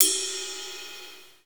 normal-hitwhistle.ogg